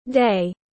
Ngày tiếng anh gọi là day, phiên âm tiếng anh đọc là /deɪ/